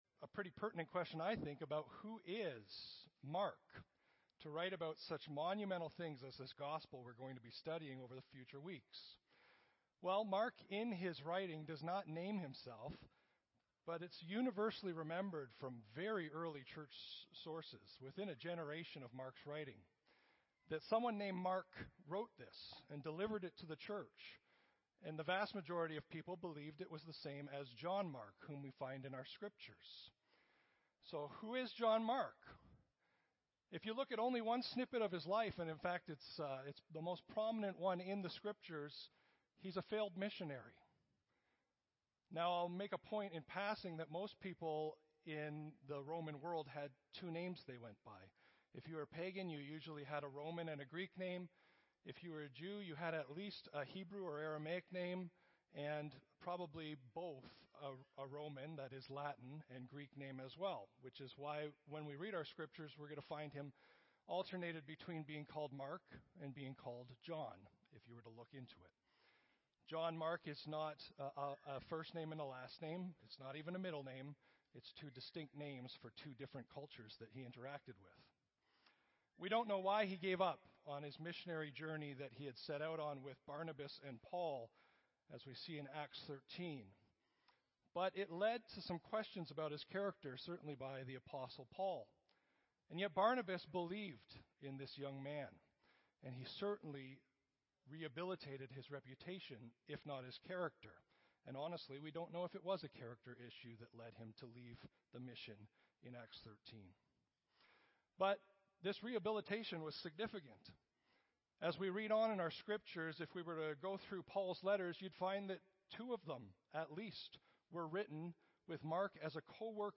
Audio Sermon Library The Gospel of Mark - Who is Mark, What is a Gospel, and How Should We Read It?